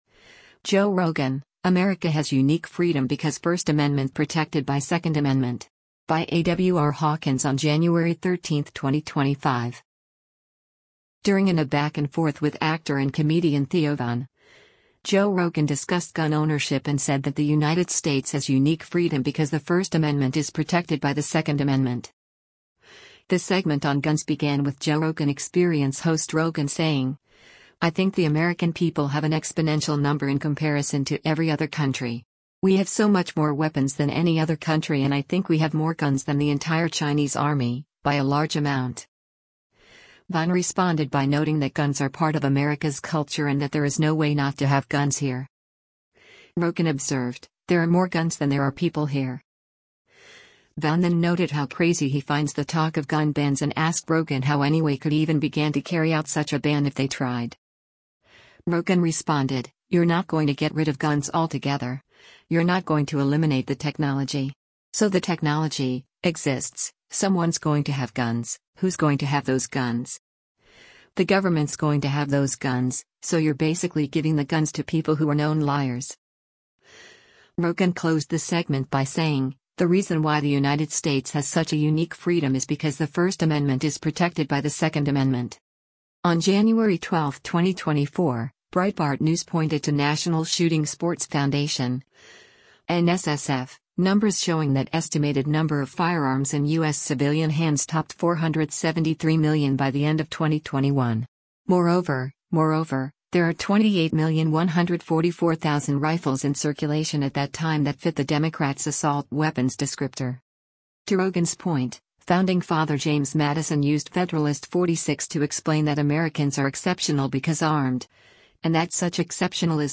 During and a back-and-forth with actor and comedian Theo Von, Joe Rogan discussed gun ownership and said that the United States has “unique freedom” because “the First Amendment is protected by the Second Amendment.”